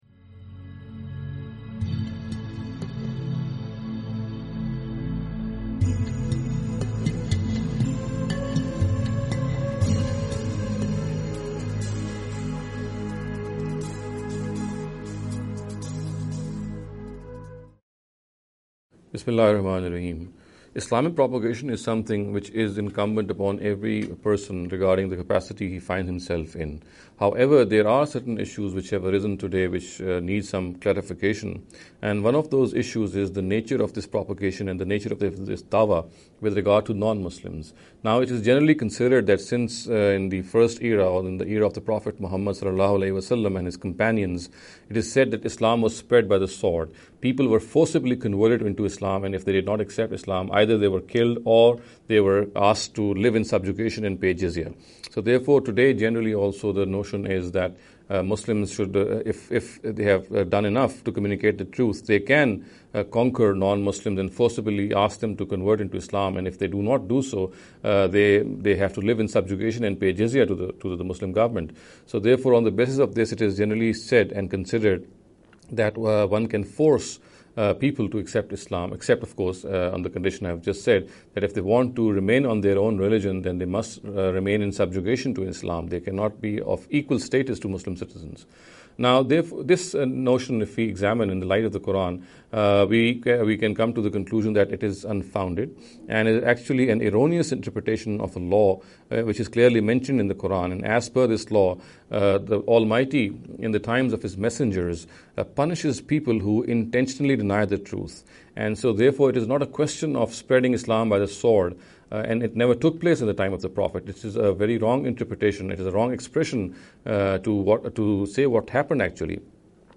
This lecture series will deal with some misconception regarding the Preaching Islam.